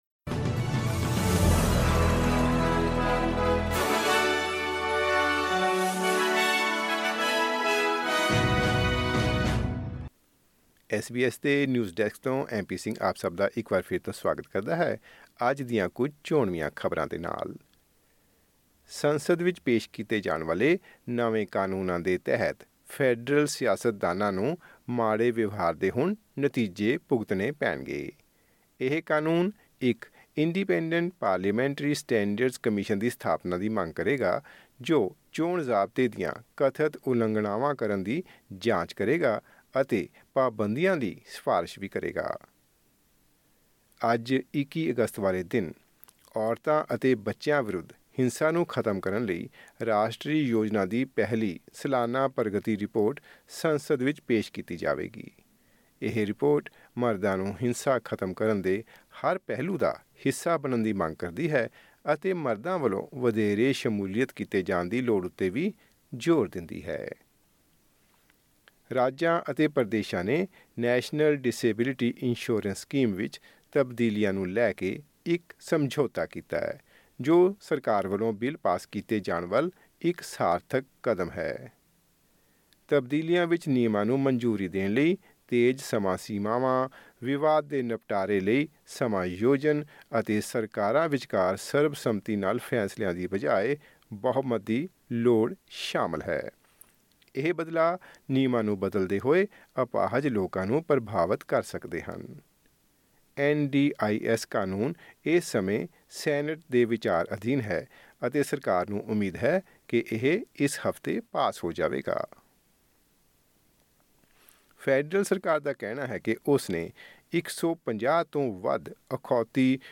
ਐਸ ਬੀ ਐਸ ਪੰਜਾਬੀ ਤੋਂ ਆਸਟ੍ਰੇਲੀਆ ਦੀਆਂ ਮੁੱਖ ਖ਼ਬਰਾਂ: 21 ਅਗਸਤ 2024